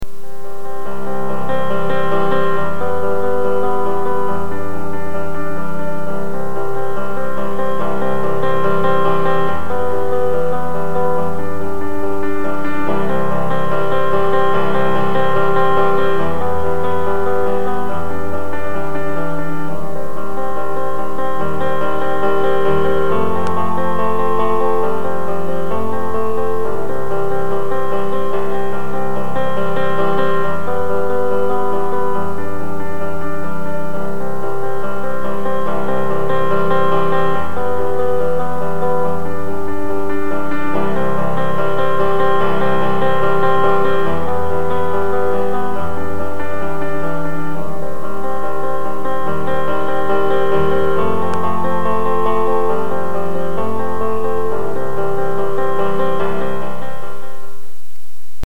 c'est une musique joué au piano, et ça colle plus pour une pub de voiture, ou pour mobile...ou pour l'environnement, ou de type gdf suez quelque fois, je sais vraiment plus laquelle c'était !!
piano.mp3